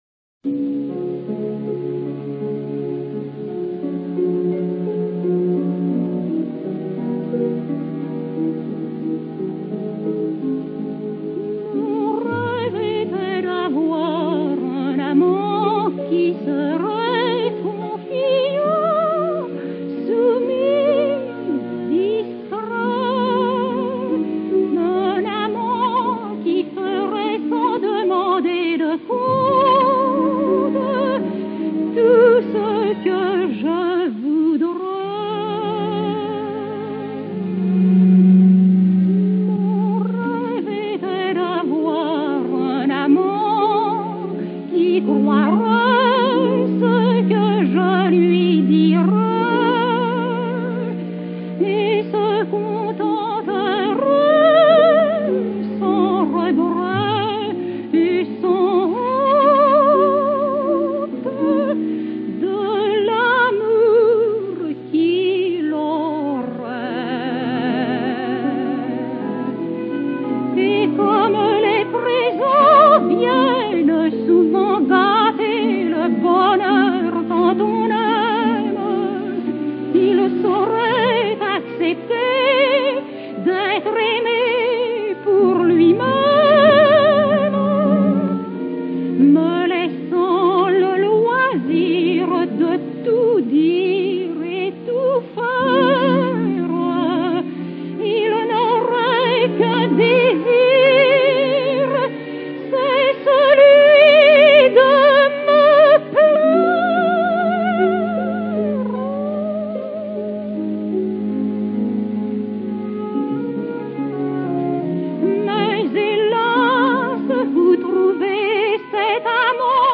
L'écriture musicale de ces mélodies présente des finesses harmoniques et mélodiques qui ne leur confèrent nullement un style de chanson franchement populaire et ce, malgré un clin d'œil au langage « titi parisien » dans les paroles (« Où's qu'y a d'bell's fill's »)[5], ou la présence de quelques notes appoggiaturées[6] ou de broderies[7] qui viennent fleurir la ligne vocale.